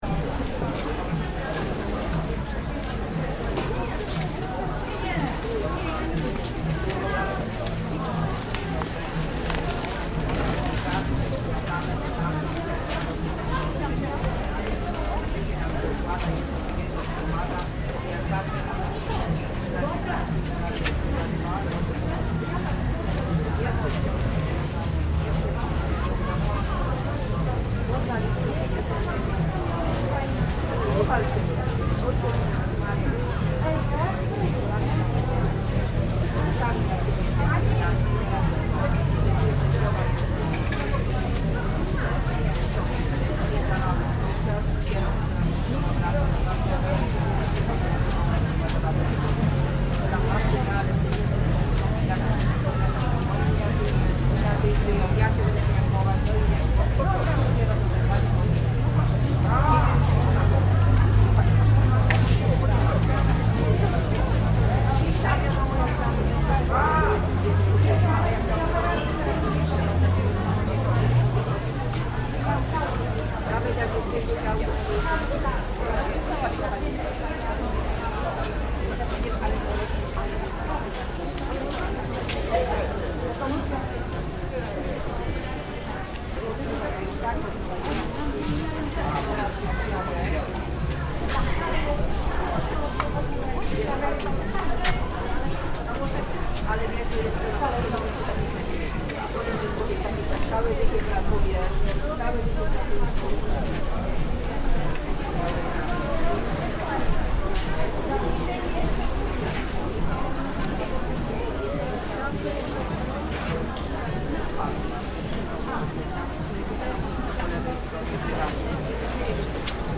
Soundwalk through the city market in Blonie, Poland.